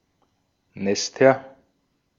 Ääntäminen
Ääntäminen Tuntematon aksentti: IPA: /ˈnɛstɐ/ Haettu sana löytyi näillä lähdekielillä: saksa Käännöksiä ei löytynyt valitulle kohdekielelle. Nester on sanan Nest monikko.